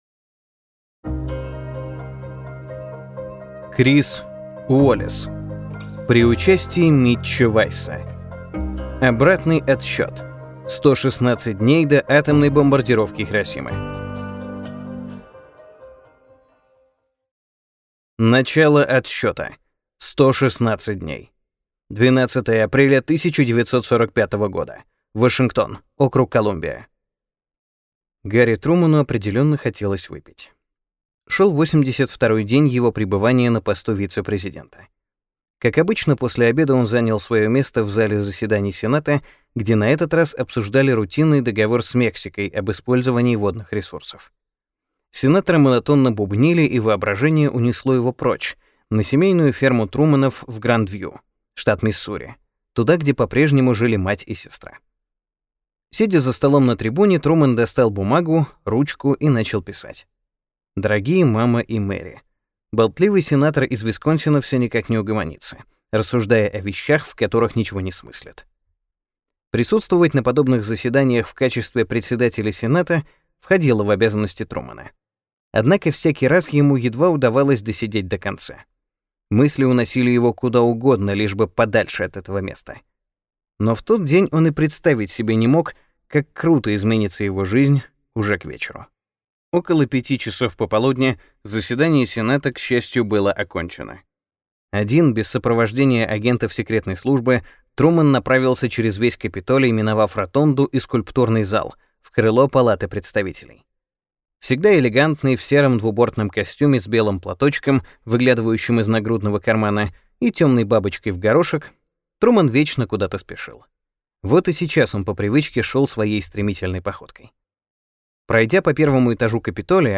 Аудиокнига Обратный отсчёт. 116 дней до атомной бомбардировки Хиросимы | Библиотека аудиокниг